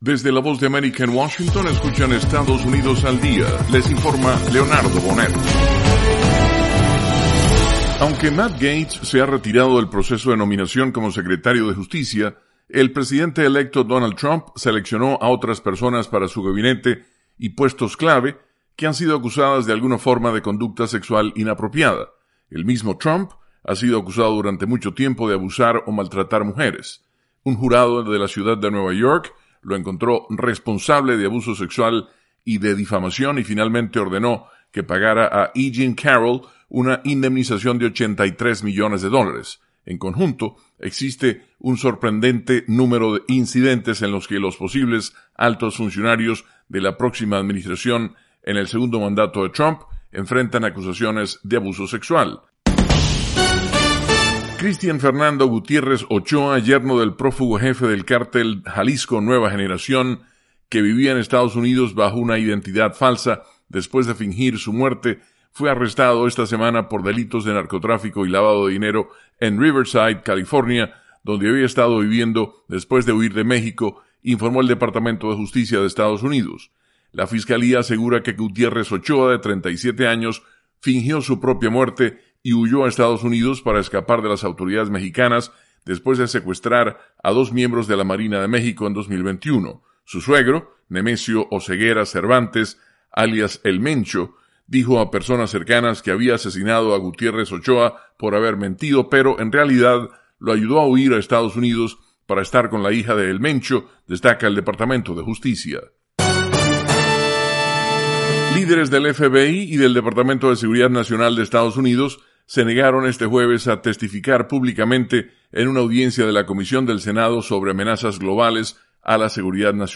Estados Unidos al Día: Con algunas de las noticias nacionales más importantes de las últimas 24 horas.